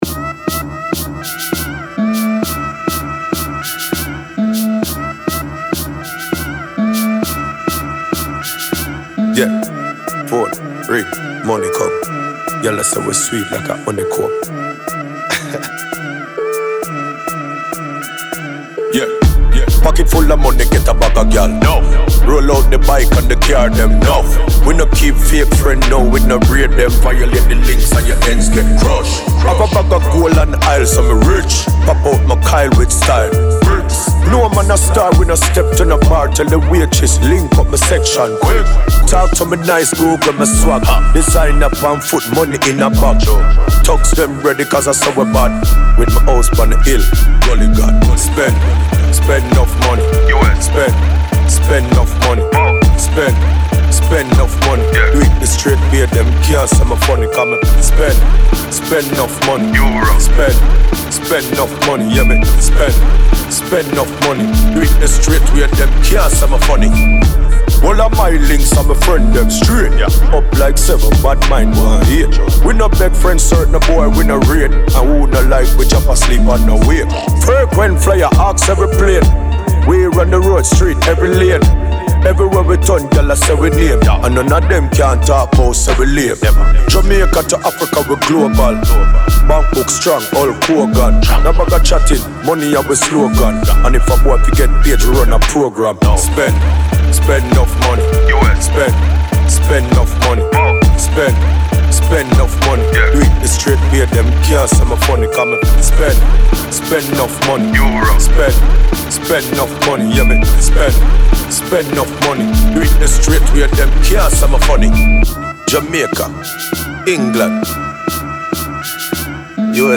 dancehall et hip hop
qu’il interprète en patois jamaïcain